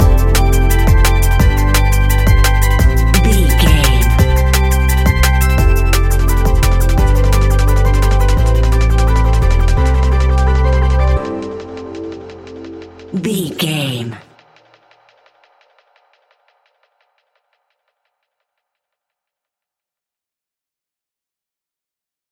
Ionian/Major
electronic
techno
trance
synths
synthwave